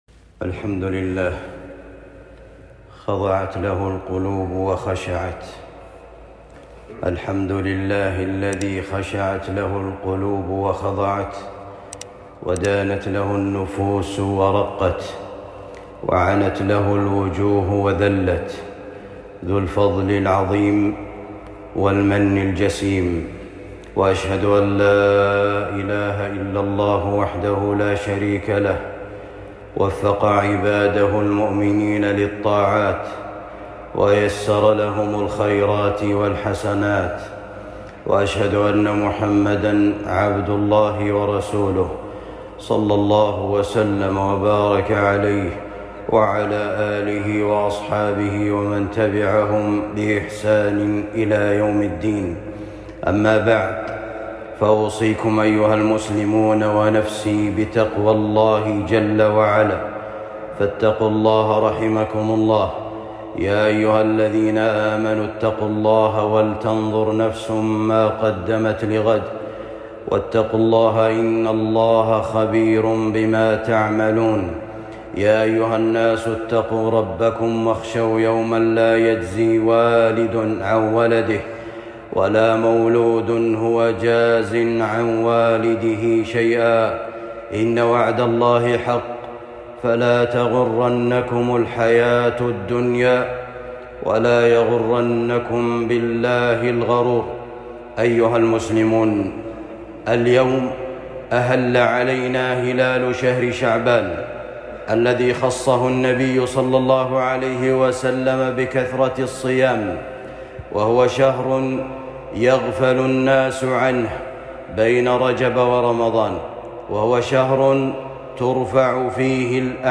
شعبان بوابة رمضان خطبة جمعة